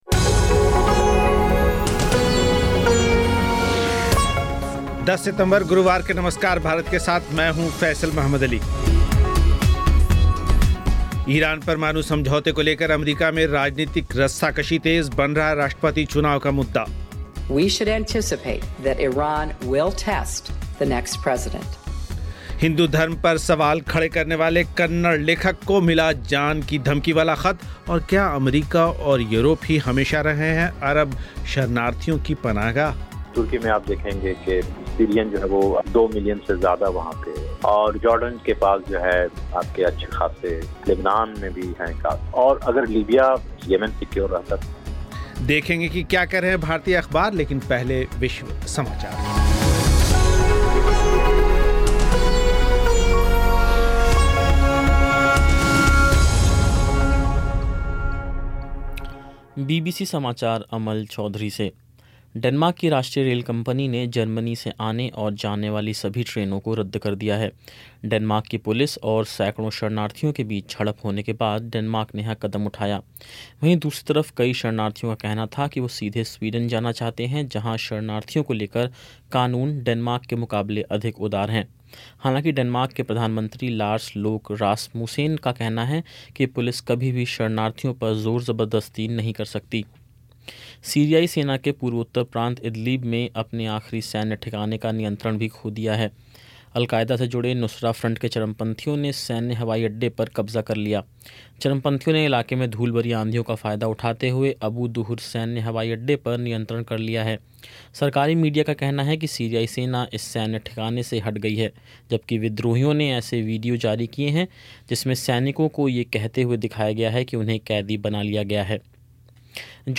सुनें बैंगलोर से एक रिपोर्ट